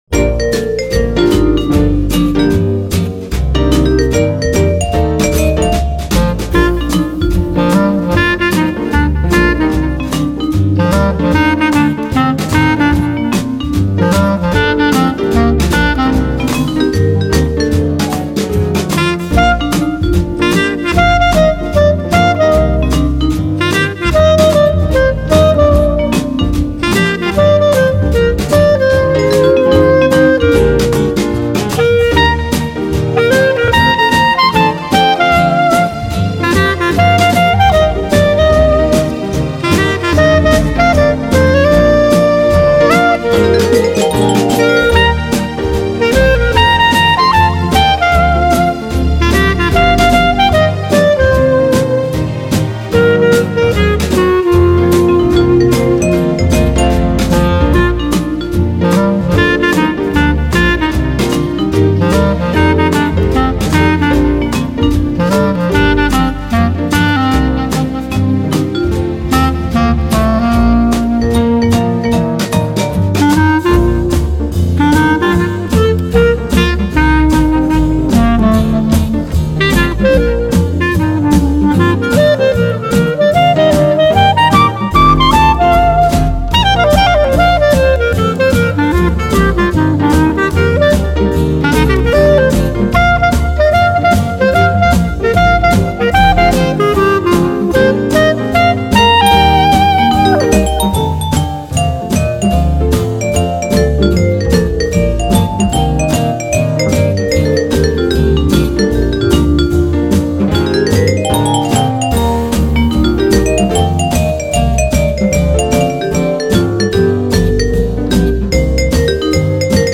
moderato swing